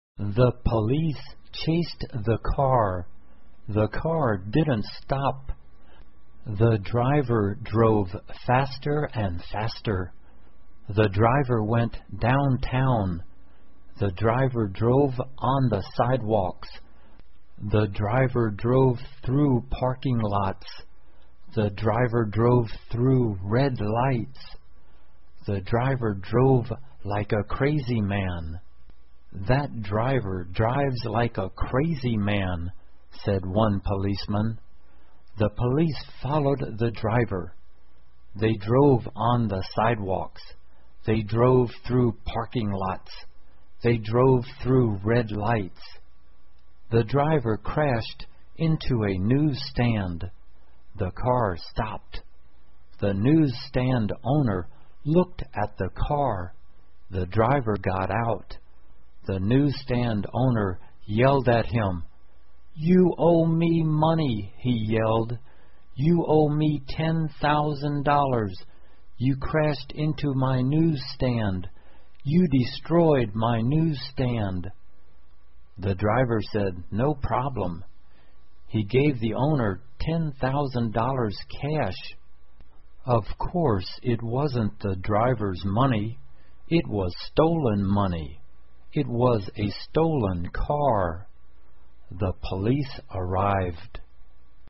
慢速英语短文听力 追车 听力文件下载—在线英语听力室